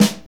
Index of /90_sSampleCDs/Northstar - Drumscapes Roland/KIT_Motown Kits/KIT_Motown Kit2x
SNR MTWN 02L.wav